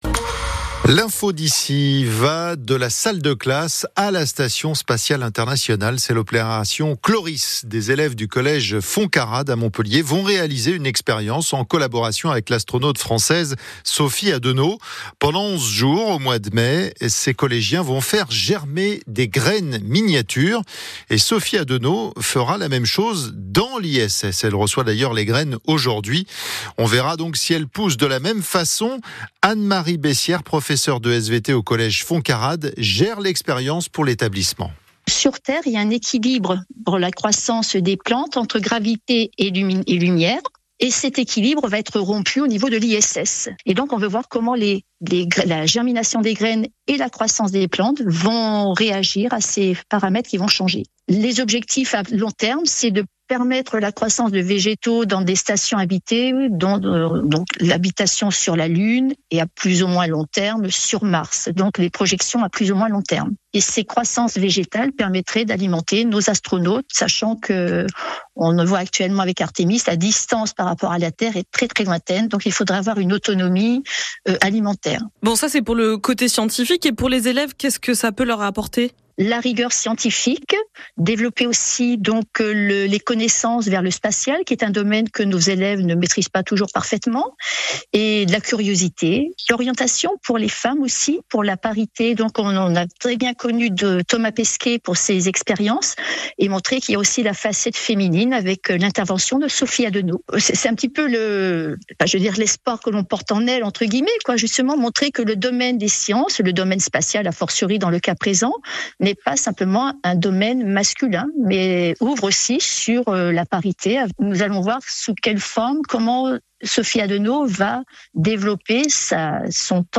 Le 08 avril 2026, le collège FONTCARRADE a été sous les projecteurs de l'actualité spatiale dans les journaux de la Matinale d'ICI Hérault pour sa participation à l'expérience ChlorISS intitulée " Prends-en de la graine ! ".